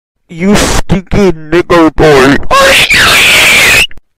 you stupid little boy aruuuuuuuuuugh sound effects free download